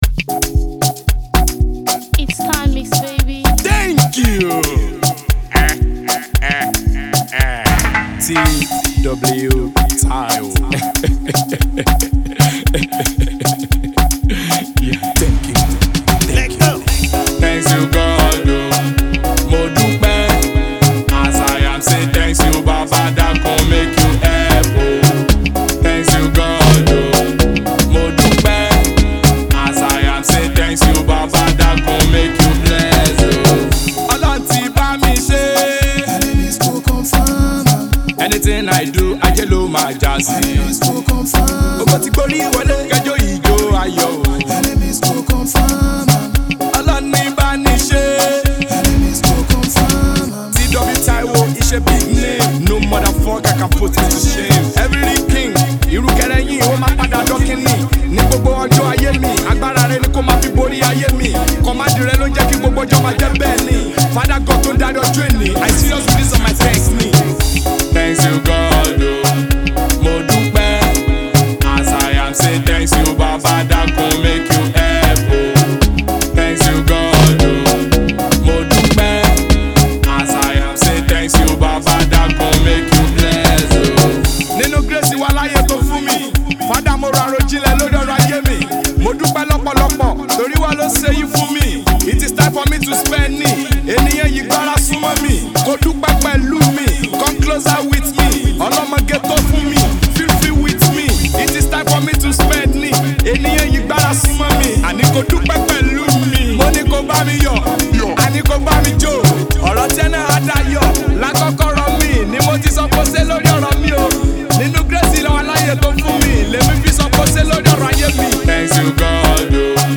heartfelt and reflective song